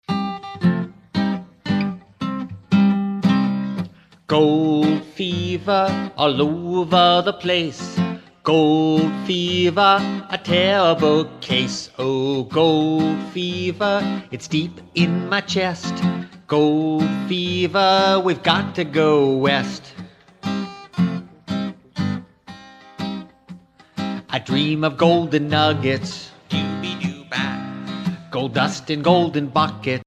A Social Studies Musical
*  Catchy melodies, dumb jokes, interesting stories